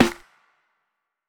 HFMSnare10.wav